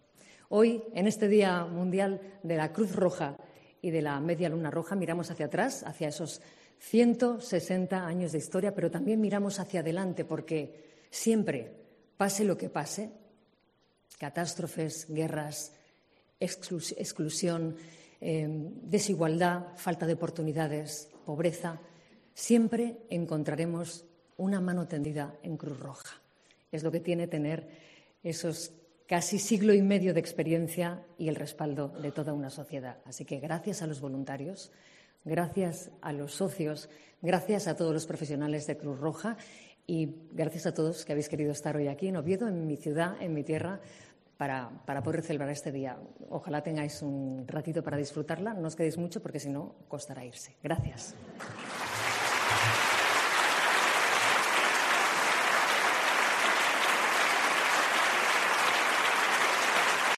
Intervención de la Reina Letizia en el Auditorio Príncipe Felipe de Oviedo